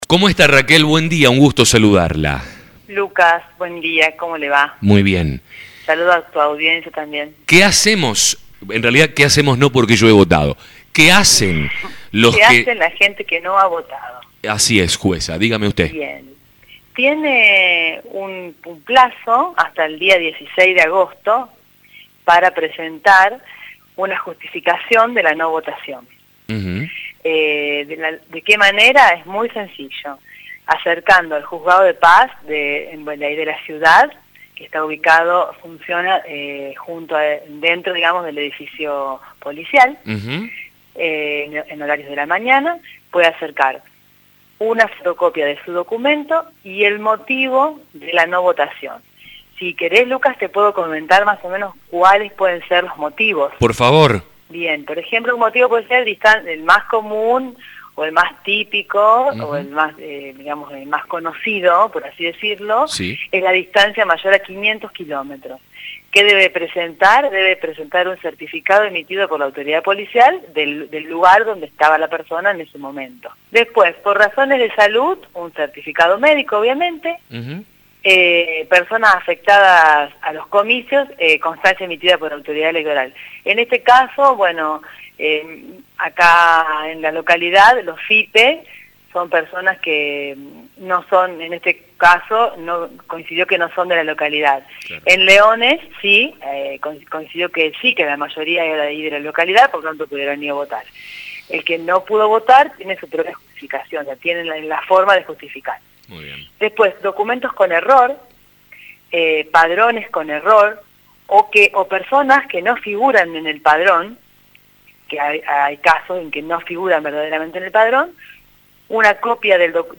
La jueza de paz de Leones, Raquel Sauri, en diálogo con La Mañana explicó qué deben hacer aquellos ciudadanos para justificar su ausencia en los comicios del pasado 12 de mayo, cuando se eligieron autoridades locales y provinciales.